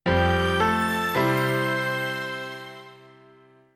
Senyal de desconnexió